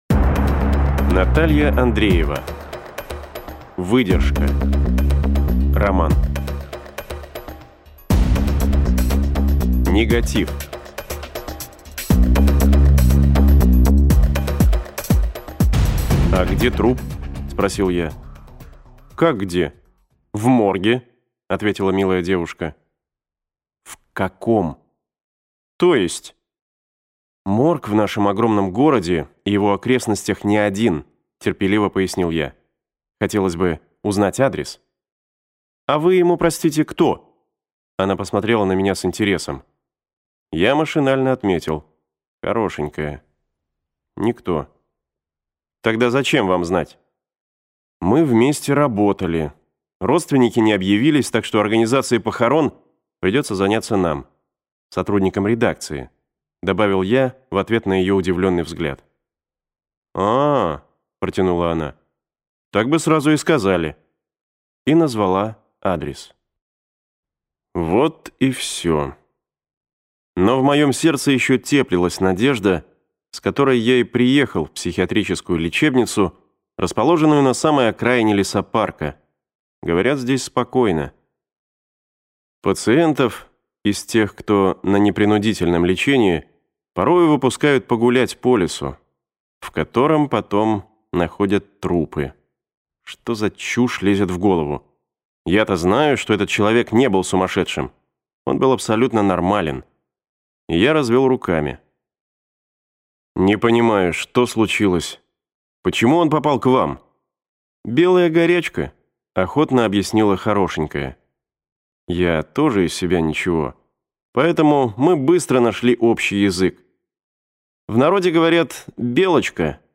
Аудиокнига Выдержка | Библиотека аудиокниг